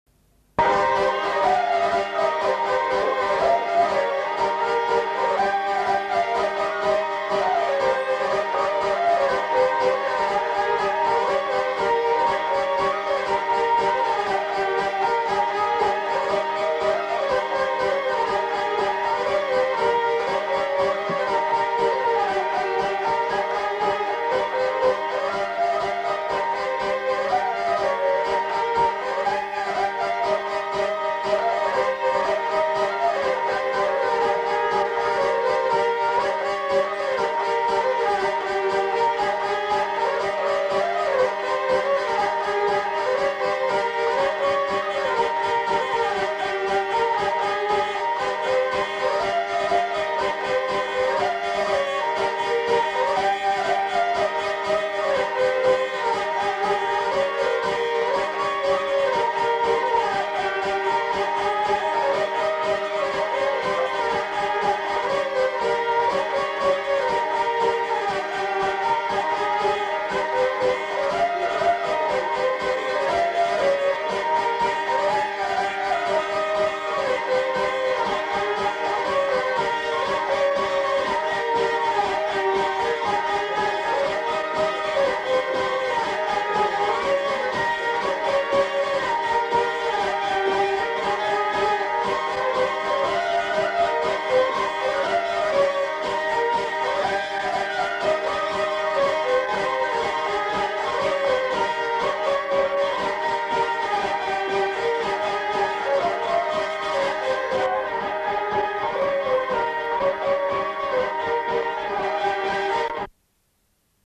Polka
Aire culturelle : Bas-Armagnac
Lieu : Mauléon-d'Armagnac
Genre : morceau instrumental
Instrument de musique : vielle à roue
Danse : polka